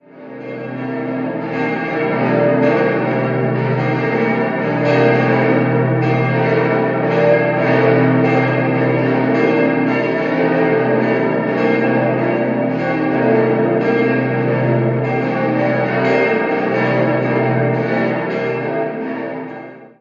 August 1948 konnte die Pfarrkirche durch Erzbischof Joseph Otto Kolb erneut geweiht werden. 5-stimmiges Geläute: a°-c'-d'-e'-g' Alle Glocken wurden im Jahr 1935 vom Bochumer Verein für Gussstahlfabrikation gegossen.